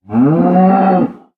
cow_say1.ogg